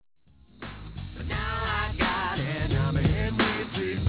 tightly focused set of jams